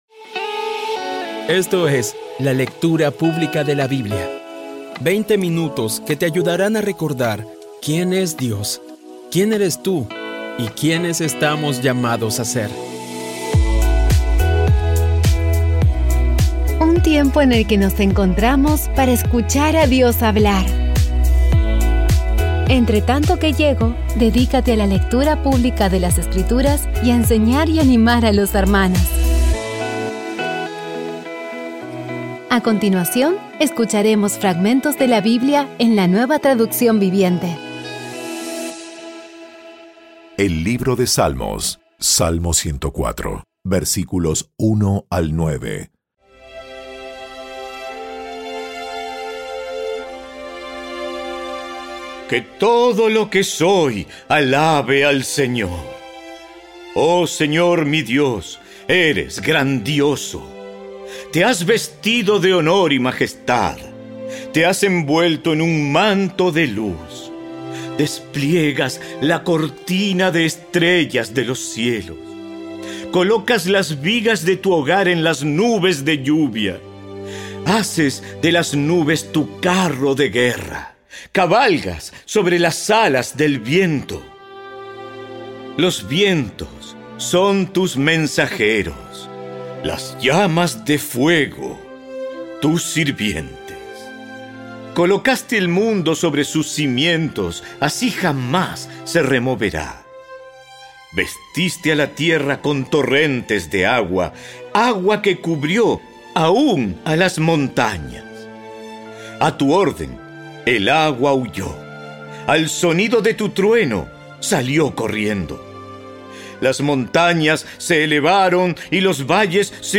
Audio Biblia Dramatizada Episodio 252
Poco a poco y con las maravillosas voces actuadas de los protagonistas vas degustando las palabras de esa guía que Dios nos dio.